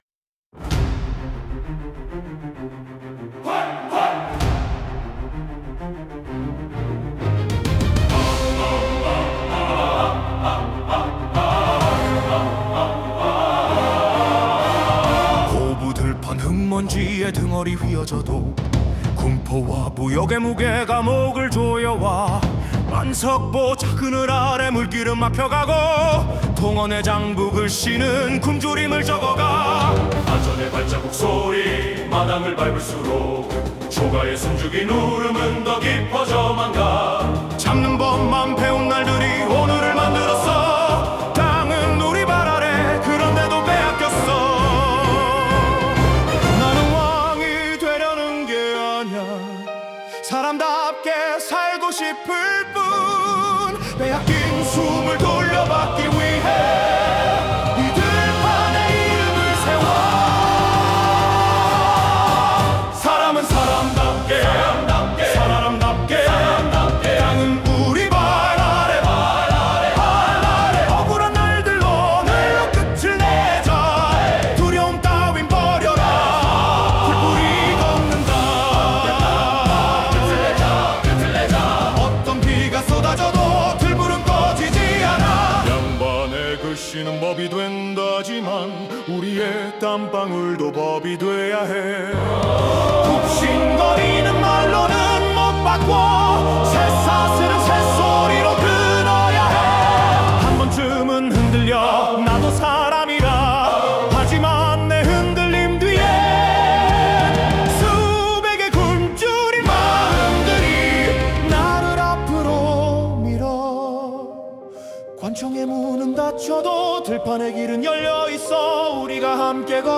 생성된 음악
다운로드 설정 정보 Scene (장면) Topic (주제) Suno 생성 가이드 (참고) Style of Music Female Vocals, Soft Voice Lyrics Structure [Meta] Language: Korean Topic: [Verse 1] (조용한 시작, 의 분위기를 묘사함) ...